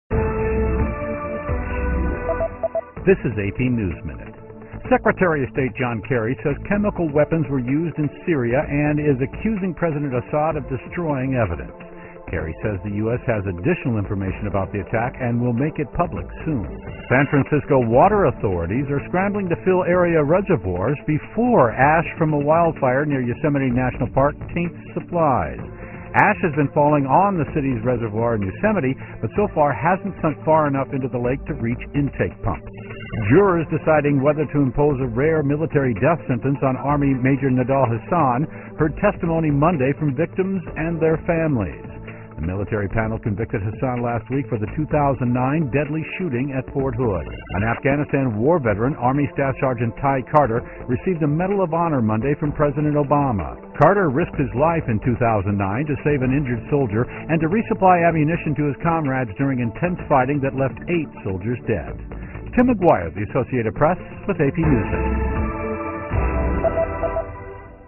在线英语听力室美联社新闻一分钟 AP 2013-08-29的听力文件下载,美联社新闻一分钟2013,英语听力,英语新闻,英语MP3 由美联社编辑的一分钟国际电视新闻，报道每天发生的重大国际事件。电视新闻片长一分钟，一般包括五个小段，简明扼要，语言规范，便于大家快速了解世界大事。